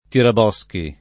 [ tirab 0S ki ]